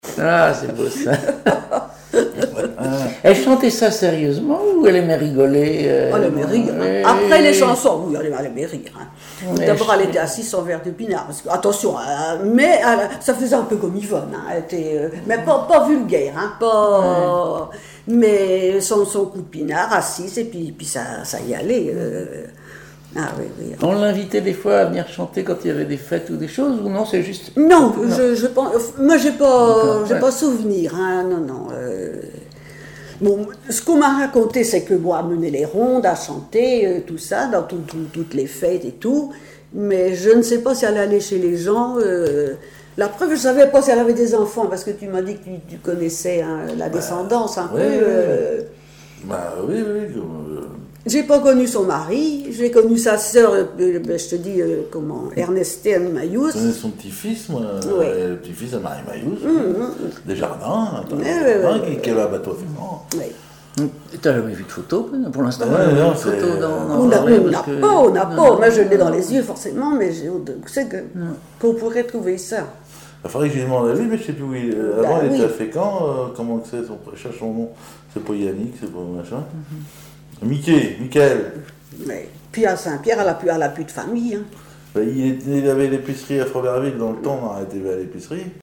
chanteur(s), chant, chanson, chansonnette
Chansons et commentaires
Catégorie Témoignage